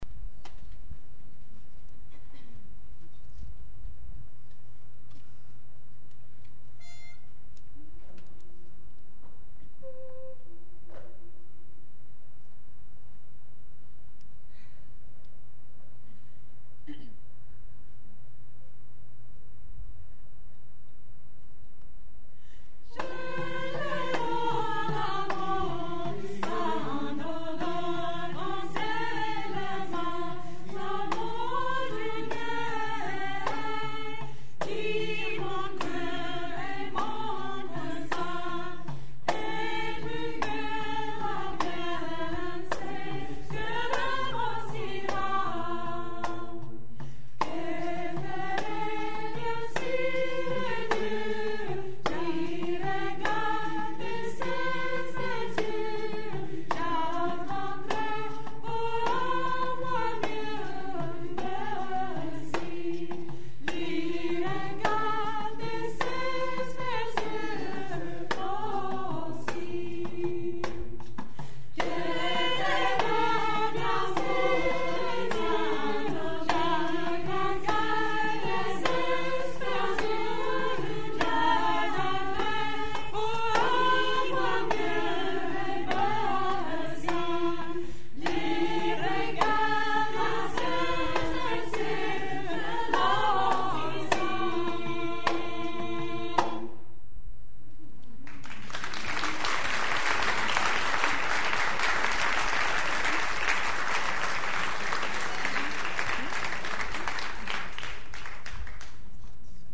Here're some a "Real Media" and MP3 clips from the concert at Saxon's River